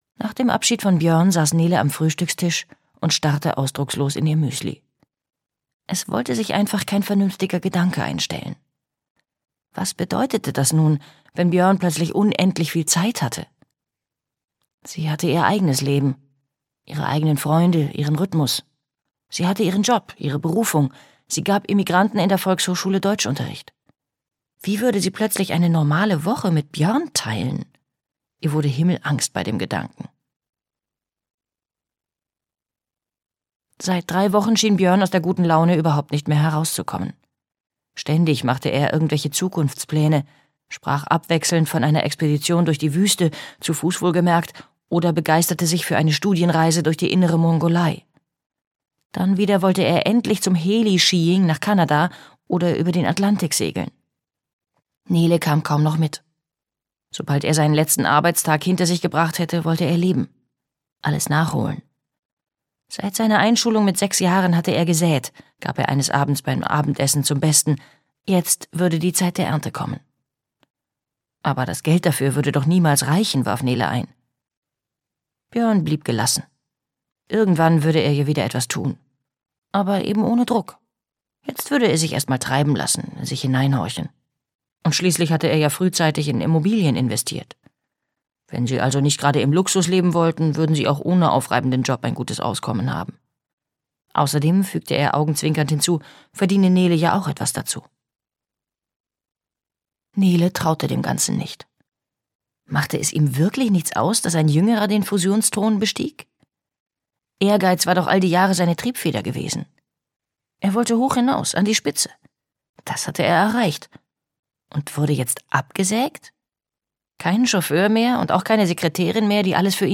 Liebling, kommst du? - Gaby Hauptmann - Hörbuch